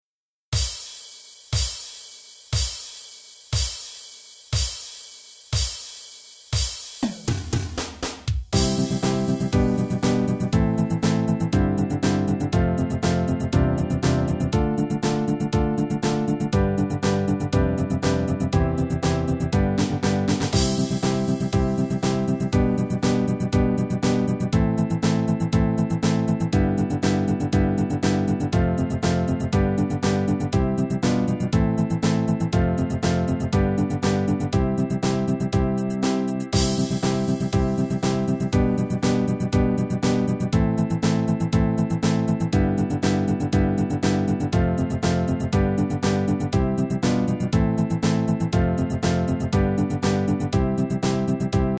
曲を聴いて曲名を答えなさい．*ただし，ドラムとコード進行しか流れません．（バス，メロディー等はなし）